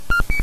handshake.mp3